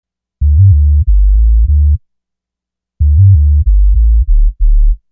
Bass 03.wav